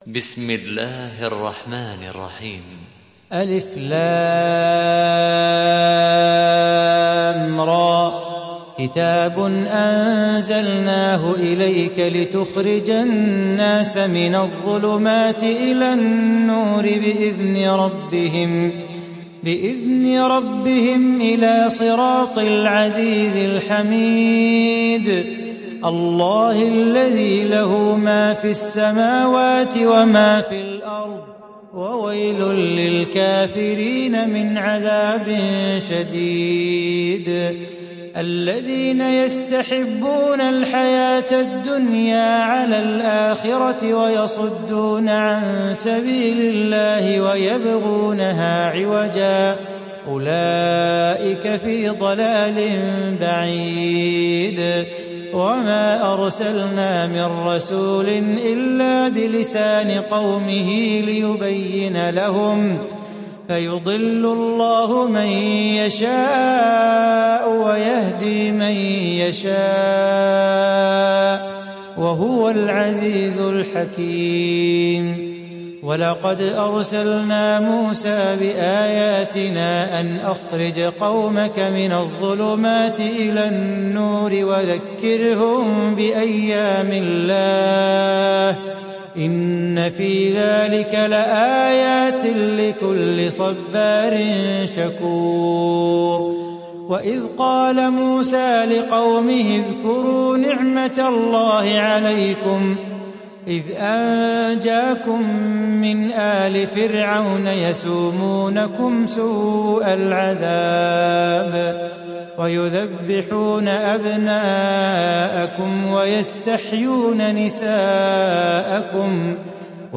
Ibrahim Suresi İndir mp3 Salah Bukhatir Riwayat Hafs an Asim, Kurani indirin ve mp3 tam doğrudan bağlantılar dinle